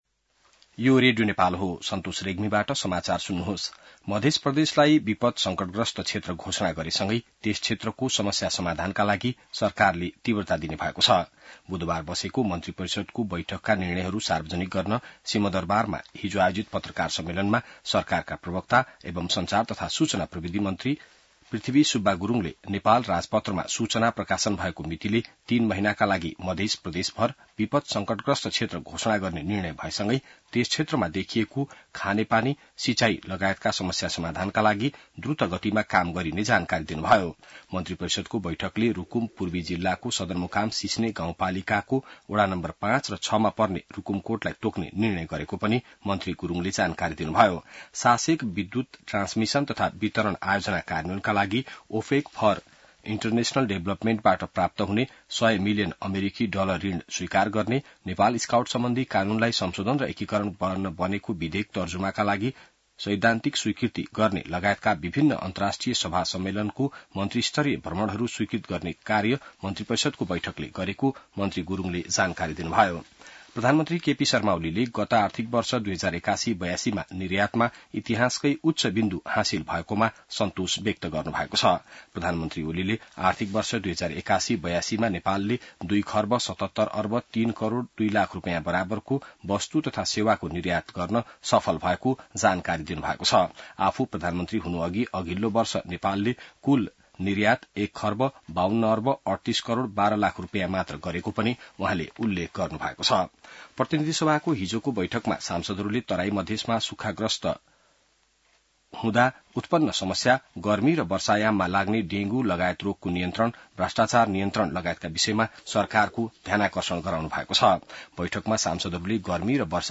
बिहान ६ बजेको नेपाली समाचार : ९ साउन , २०८२